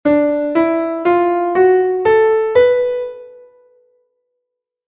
Blues Maior
blues_maior.1.mp3